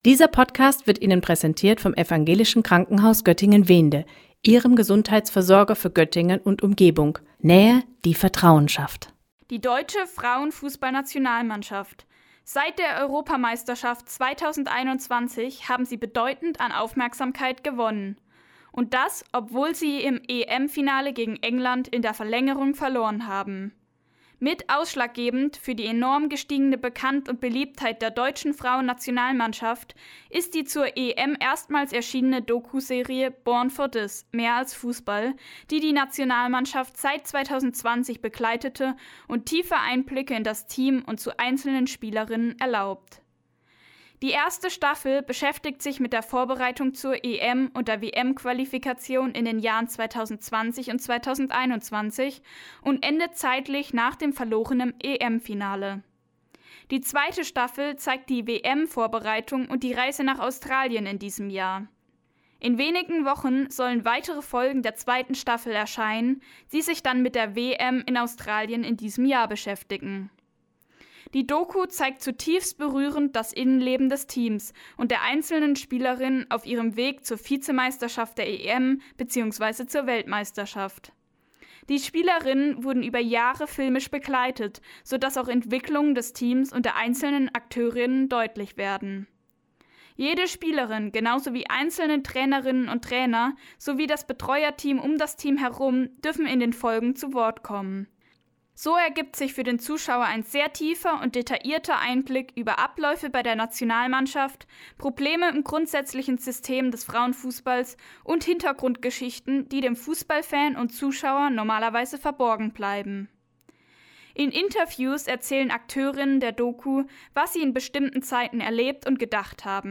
Mehr über die Doku-Serie „Born for this – Mehr als Fußball“ erfahren Sie nun in einer Rezension